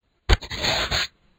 door sounds.
door.ogg